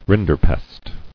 [rin·der·pest]